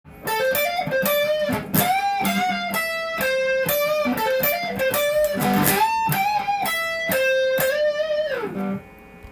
フロントピックアップで弾くとメロウな感じで
エリッククラプトンやジェフベックも弾けそうな雰囲気です。